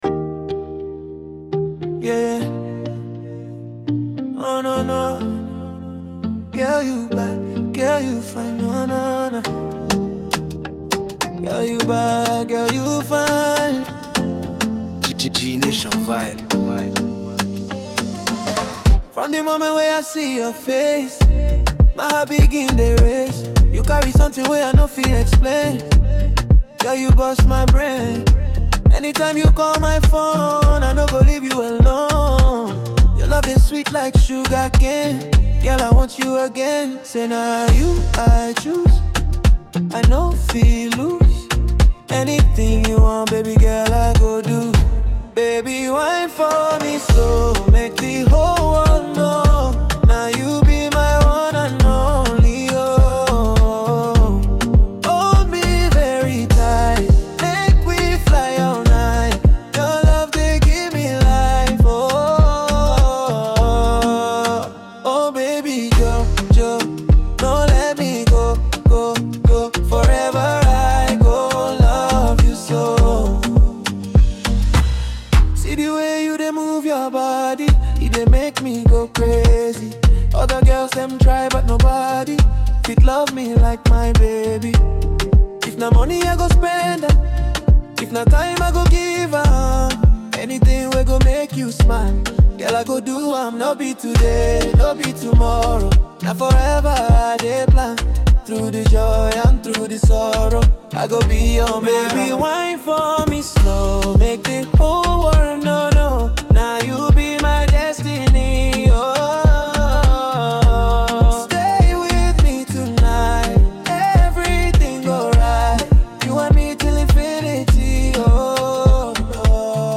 a popular Nigerian Afrobeats artist
blends elements of romantic lyrics and rhythmic beats